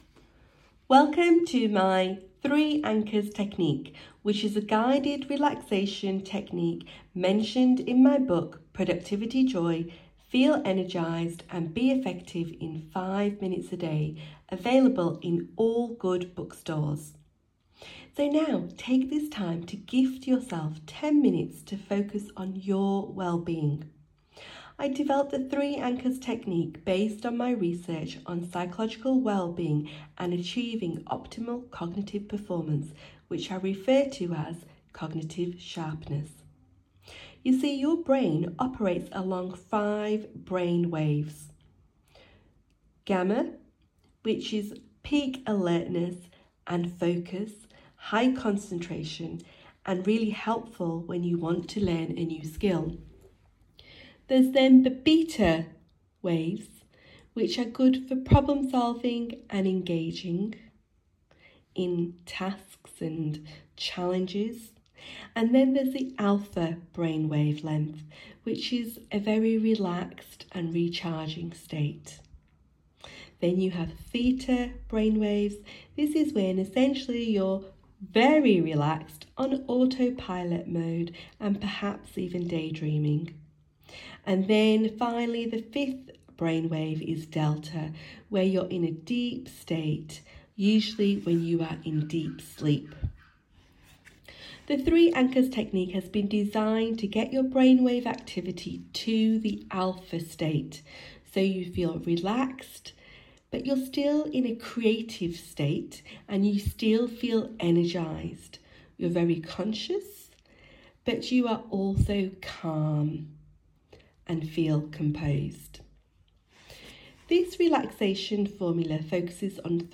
Use this eight-minute relaxation exercise when you get into bed before you fall asleep. This technique helps you calm your mind, relax your body and prime your brain to focus on joy and smiles.